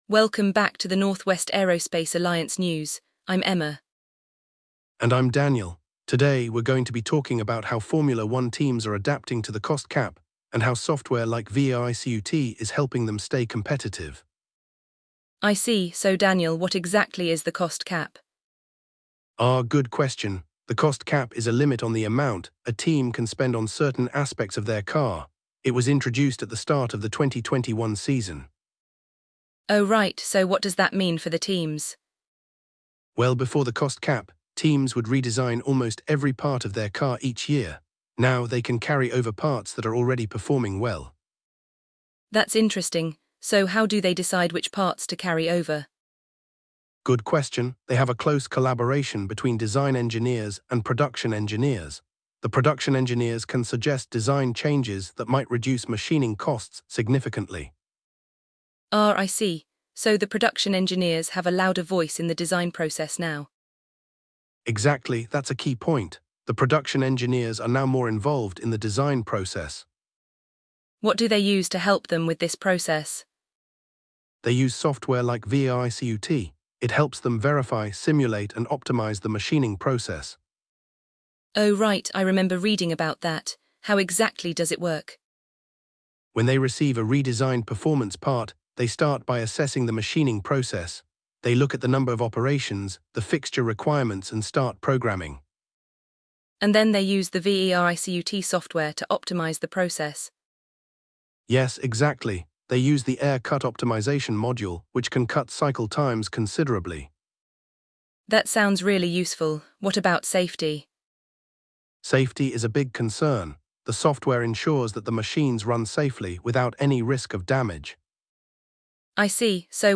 The conversation delves into the use of software like VERICUT, which verifies, simulates, and optimizes machining processes, significantly reducing cycle times and ensuring safety.